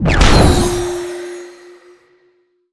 spell_harness_magic_01.wav